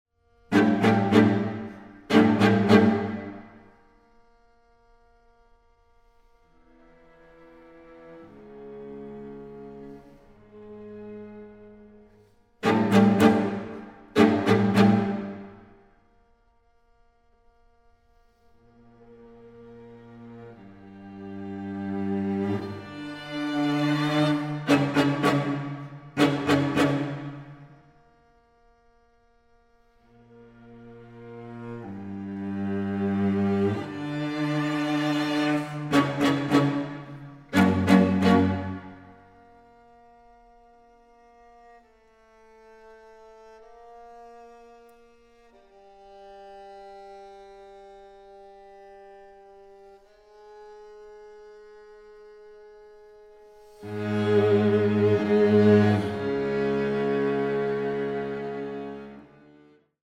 Streichquartett
Aufnahme: Festeburgkirche Frankfurt, 2024